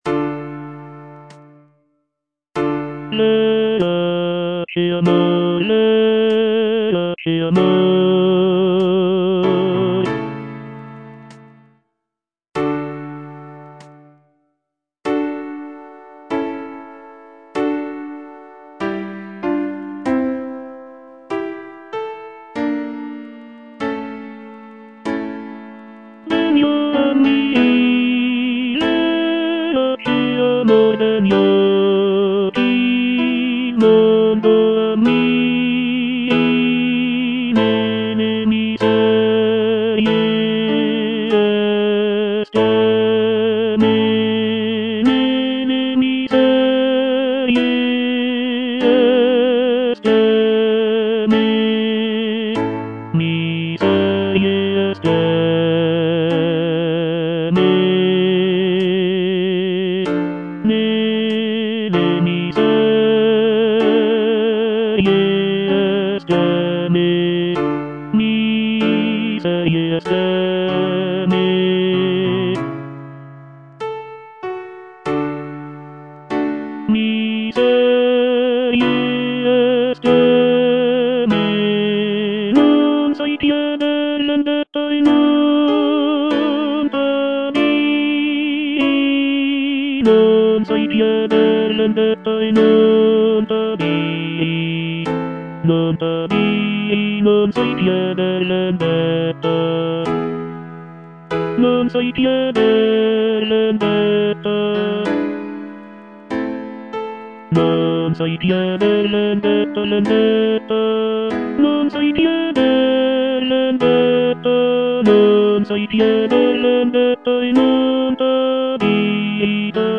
C. MONTEVERDI - LAMENTO D'ARIANNA (VERSION 2) Coro IV: Verace amor - Tenor (Voice with metronome) Ads stop: auto-stop